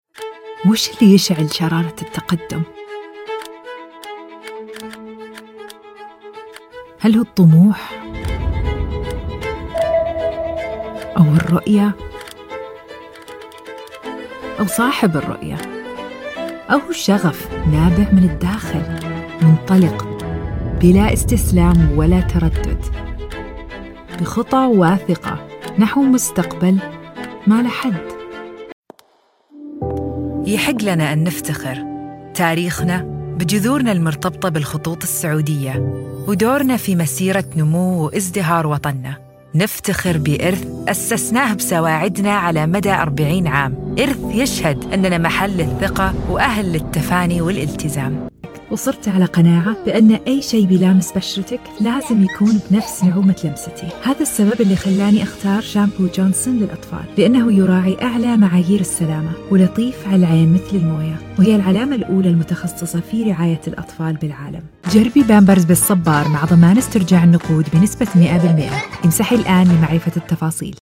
Bonjour ! Je suis un comédien de doublage primé, parlant couramment l'anglais (accent britannique et accent arabe) et l'arabe...
Fiable
Autoritaire
Sur de soi